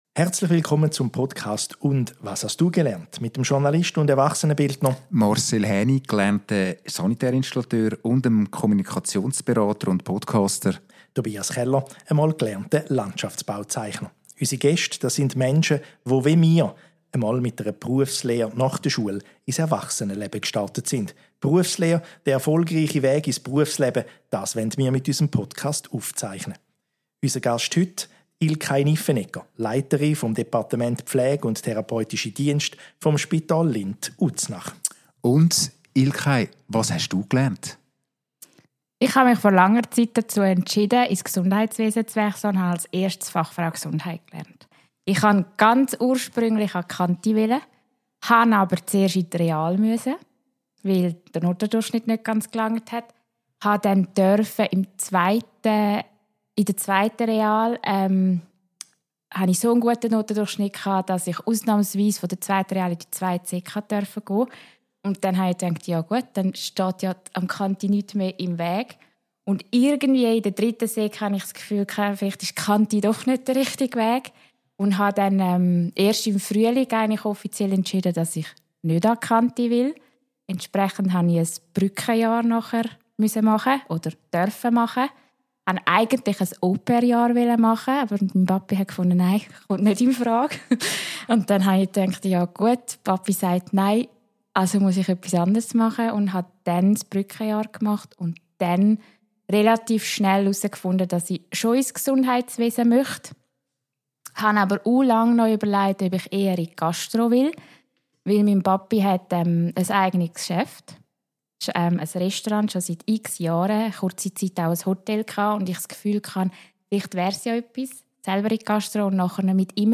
Was sie antreibt – und warum Stillstand für sie keine Option ist. Ein Gespräch mit Herz, Humor und viel Power!